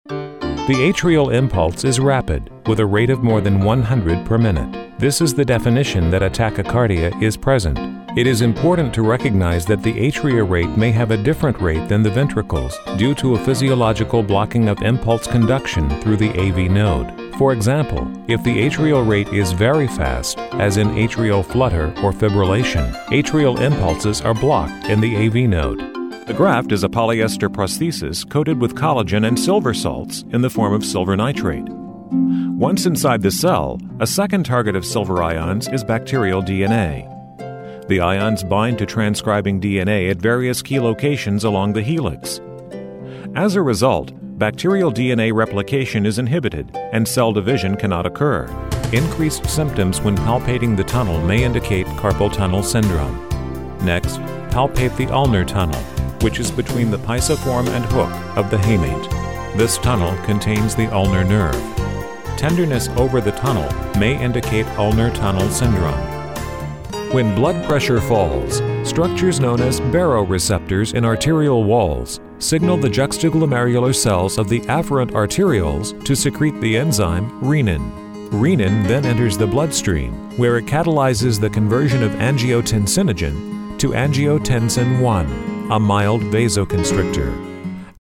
Englisch (US)
Medizinische Erzählung
Im mittleren Alter
Senior
FachmannVertrauenswürdigKonversationGlaubhaftFesselndZuversichtlichMitfühlendWarmNatürlichInformativArtikulierenFreundlich